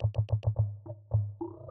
Destroy - LoopPerc.wav